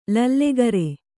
♪ lallegare